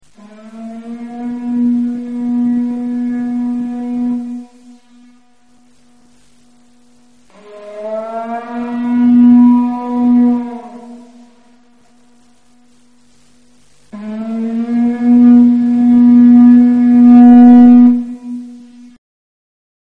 Tierhorn